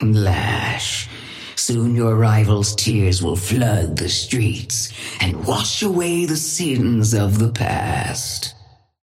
Sapphire Flame voice line - Lash, soon your rival's tears will flood the streets and wash away the sins of the past.
Patron_female_ally_lash_start_04.mp3